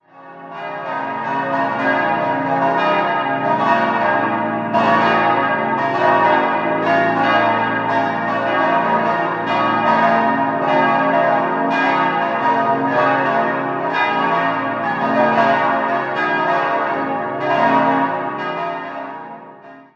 In den Jahren 1985/86 erfolgte im Rahmen einer groß angelegten Renovierung eine Überarbeitung der Ausmalung. 5-stimmiges Geläut: b°-des'-es'-f'-as' Die Glocken wurden 1950 von Benjamin Grüninger in Villingen gegossen.